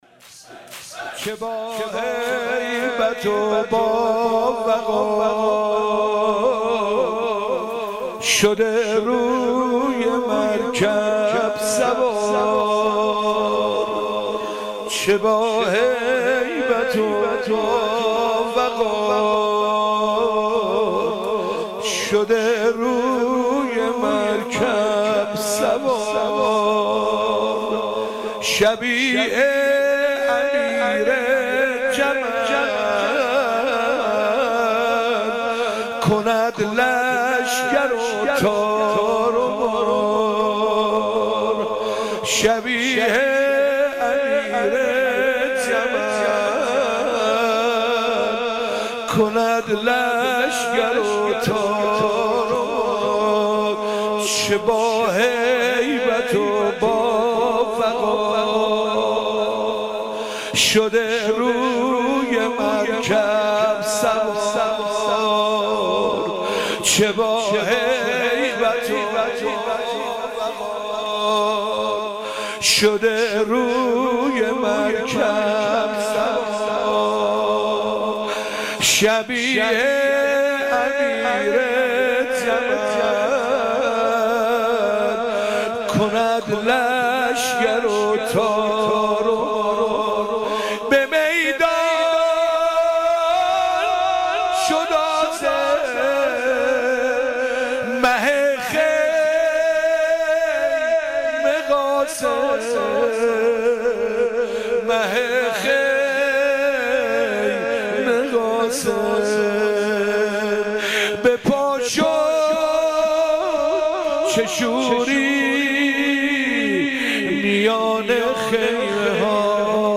نوحه - چه با هیبت و با وقار